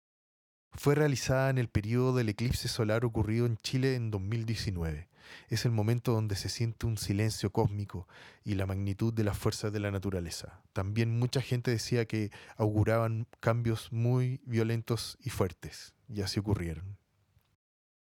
Eclipse Colección Titium-Glusberg Compartir en Facebook Compartir en Twitter Share on WhatsApp Audioguía Fue realizada en el período del eclipse solar ocurrido en Chile el 2019.